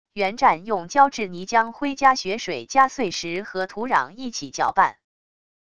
原战用胶质泥浆灰加雪水加碎石和土壤一起搅拌wav音频